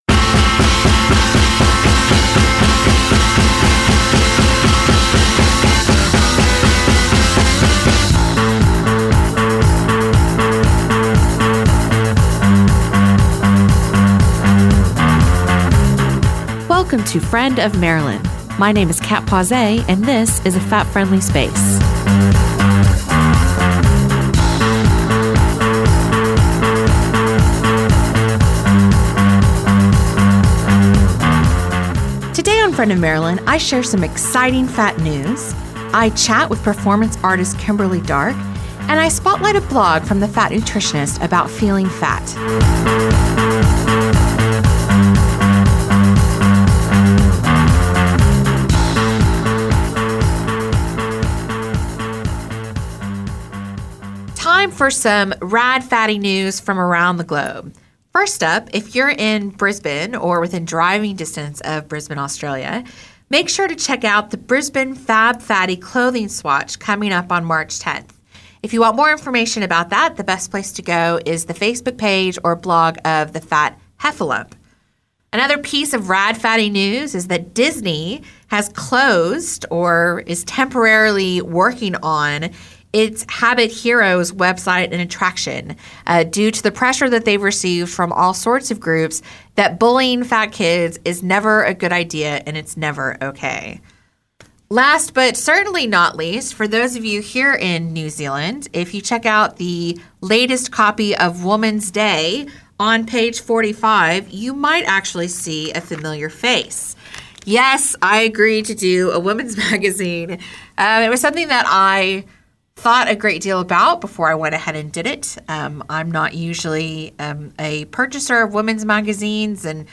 access radio